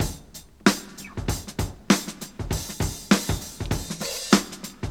• 97 Bpm Fresh Drum Groove D# Key.wav
Free drum loop sample - kick tuned to the D# note. Loudest frequency: 3128Hz
97-bpm-fresh-drum-groove-d-sharp-key-MnH.wav